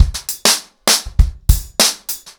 BellAir-A-100BPM__1.9.wav